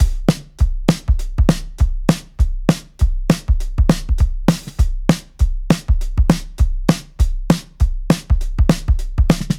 定番ビート – モータウン・ビート
このノリノリなリズムは、俗に「モータウン・ビート」などと呼ばれます。ウキウキ感があっていいですよね。